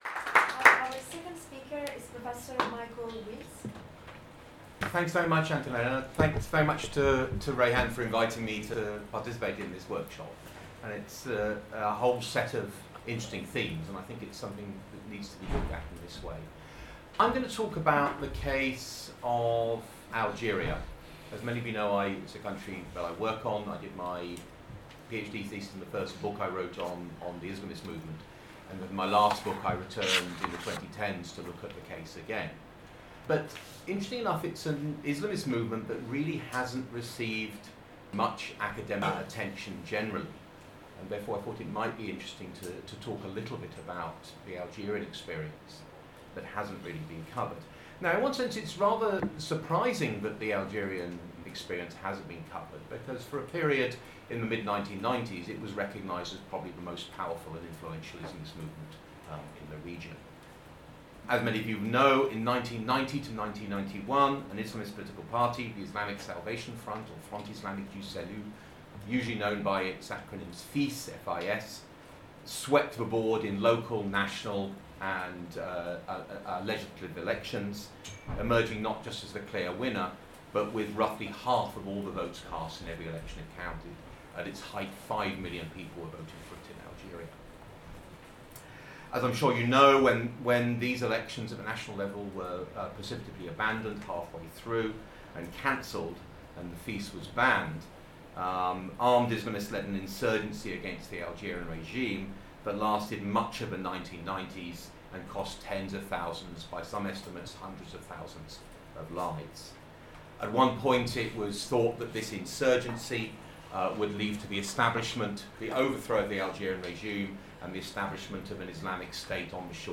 Guest speaker contributing to the 1-day workshop: Transnational Islamic Movements: Global and Local Realities, held on Friday 29 November 2024.